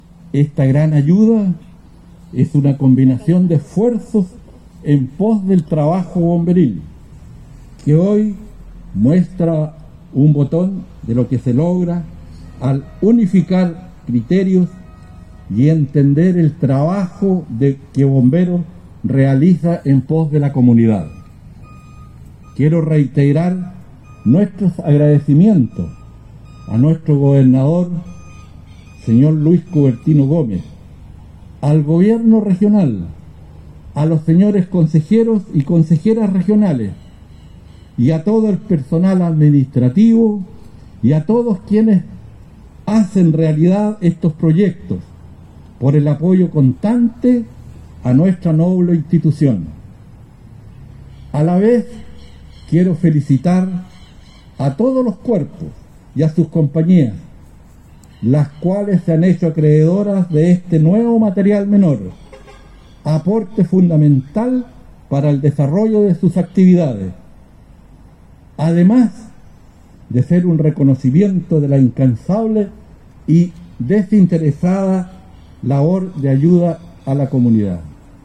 En los jardines de la casa Prochelle II, el Gobernador Regional Luis Cuvertino junto a los Consejeros Regionales Elías Sabat, Catalina Hott, Ariel Muñoz y Héctor Pacheco entregaron 312 equipos de respiración a 78 compañías de Bomberos de la Región de Los Ríos, correspondientes a Valdivia, Panguipulli, La Unión, Río Bueno, San José de la Mariquina, Futrono, Lanco, Crucero, Lago Ranco, Los Lagos, Paillaco, Choshuenco, Corral, Reumén, Malalhue, Máfil, Huellelhue, Antilhue, Mehuín y Pichirropulli.